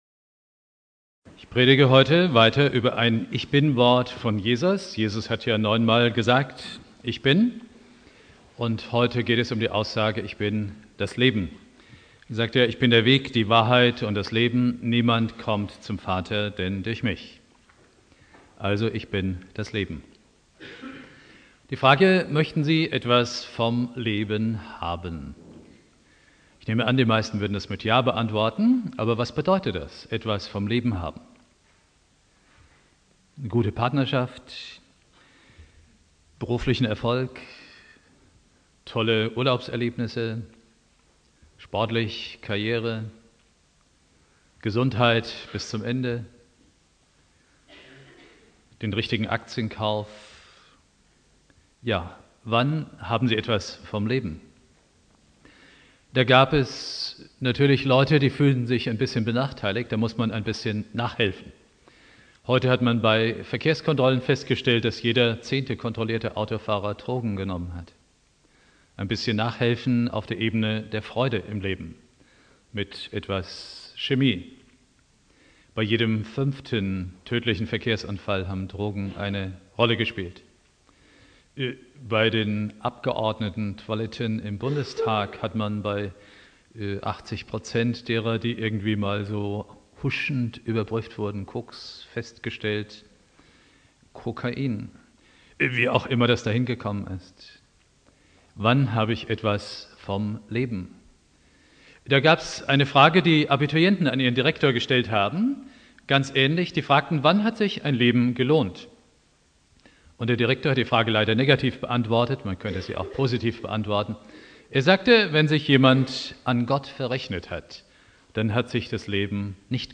Predigt
3.Advent